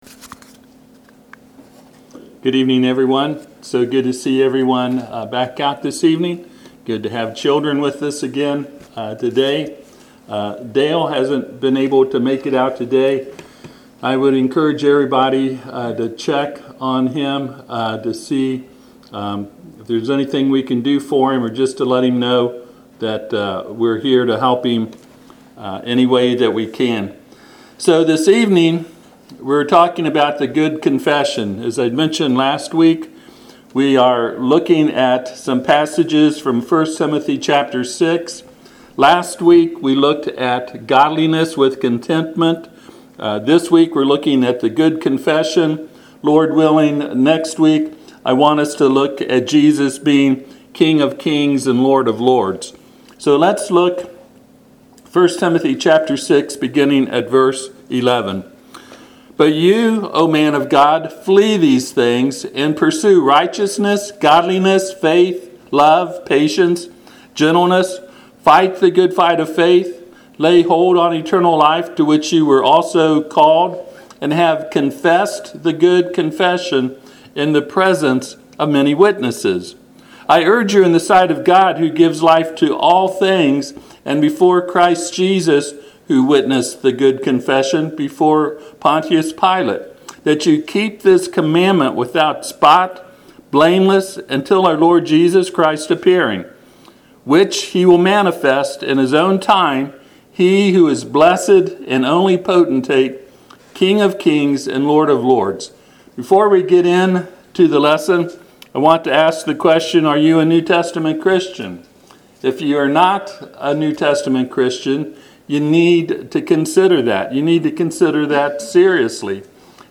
Passage: 1Timothy 6:11-13 Service Type: Sunday PM